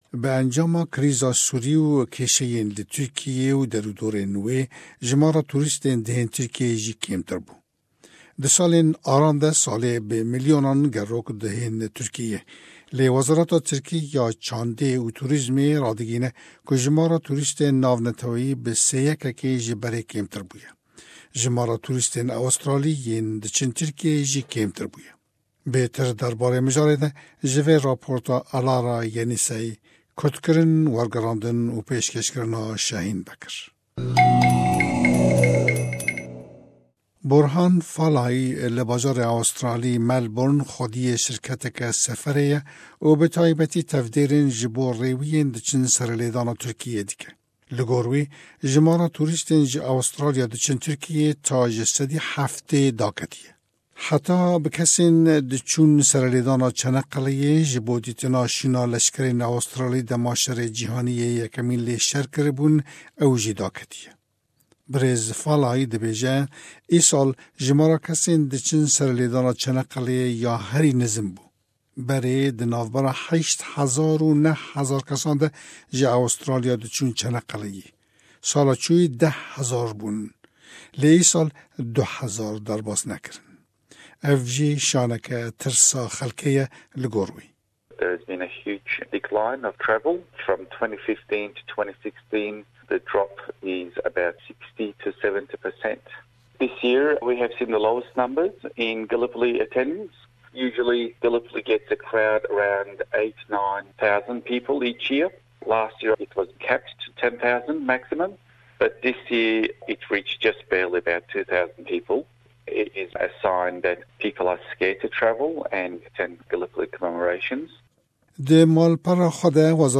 Ev Raport xwe bi mjiara daketina jimara tûrîstên dichin Tirkiyeyê ve peywendîgir dike û sedeman xuya dike, ka chima kêmtir tûrîst van rojan dichin Tirkiyeyê. Gelo dîtina kesên ku karê wan bi hatina tûrîstan ve peywendîgir e chi dibêjin? Guh bide raporta me.